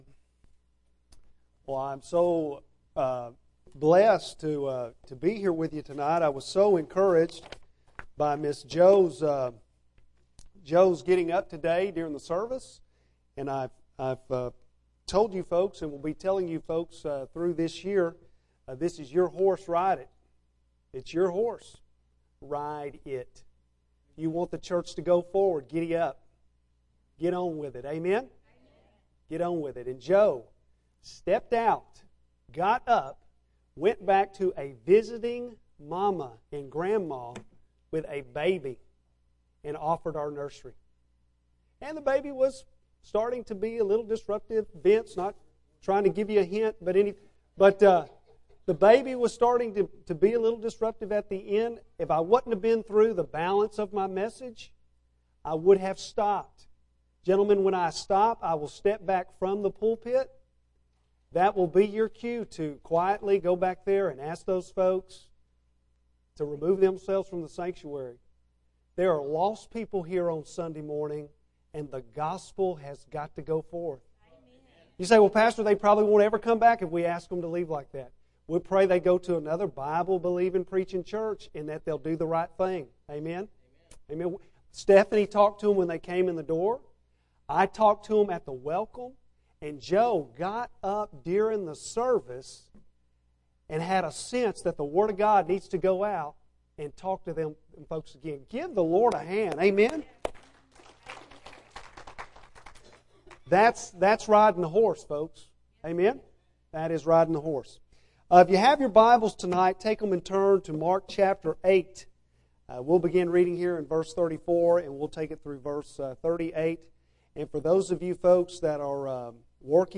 Bible Text: Mark 8:34-38 | Preacher